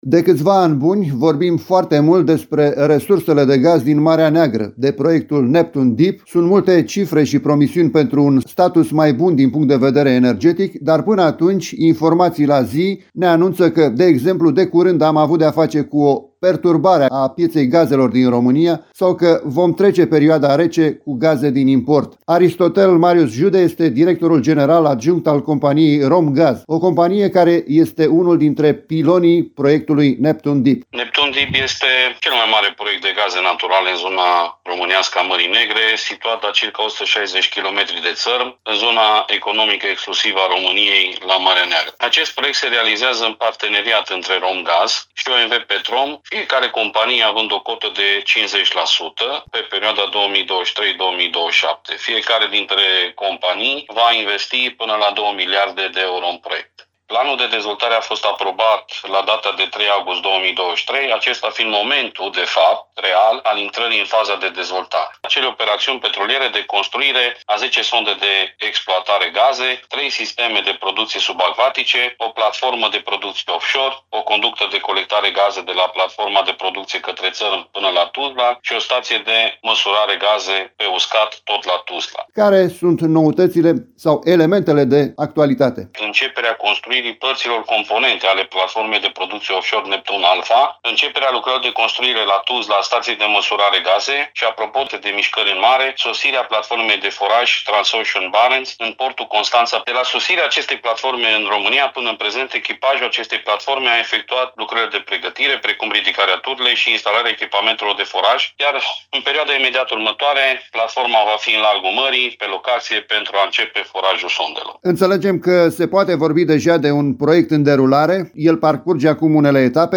Primele gaze vor fi extrase la începutul anului 2027, iar resursele existente în perimetrul de exploatare vor permite acoperirea orizontului de timp al tranziției energetice a României. Mai multe detalii aflăm din interviul